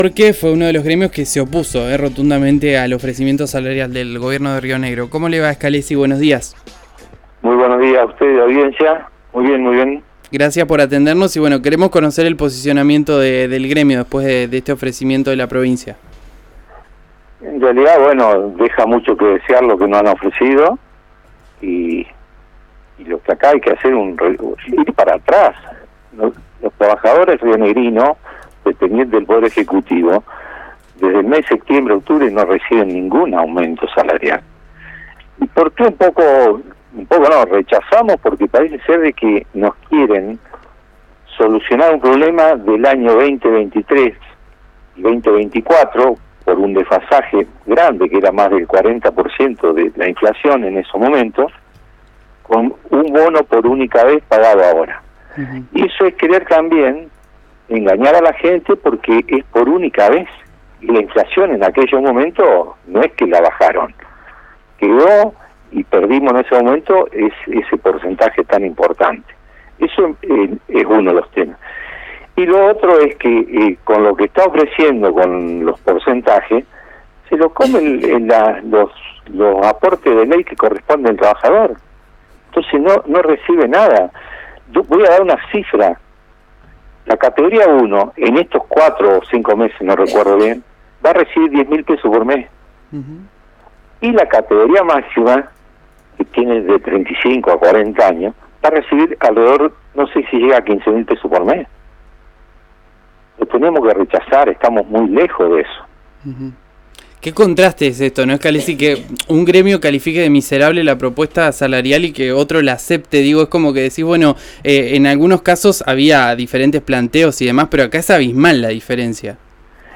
en diálogo con RÍO NEGRO RADIO